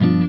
JAZZCHORD2.wav